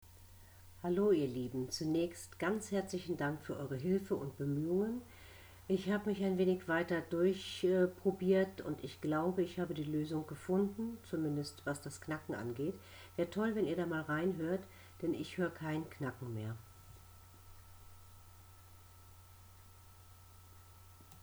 Testaufnahme gestartet und mit diesem Programm "speichern als" benutzt.
Also als mp3 gespeichert und jetzt höre ich kein Knacken mehr.
Über das Rauschen bitte hinweghören,...darum kann ich mich später kümmern.